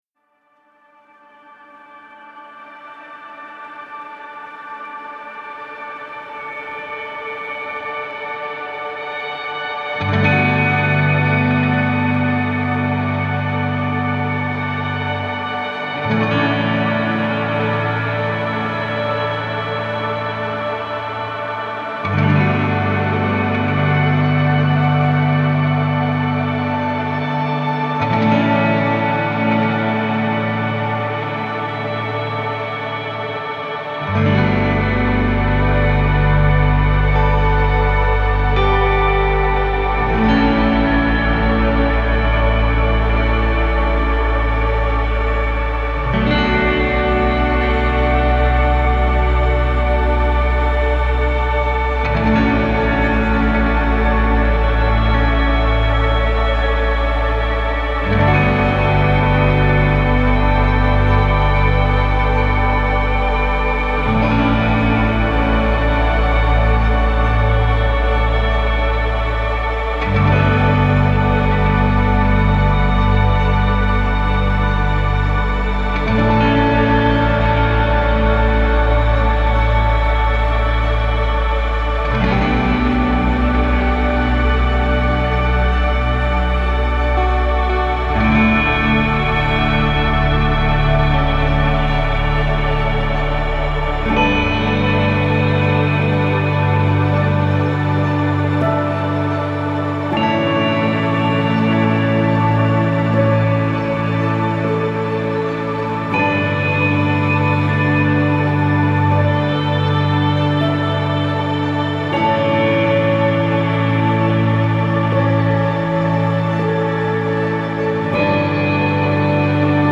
موسیقی بی کلام پست راک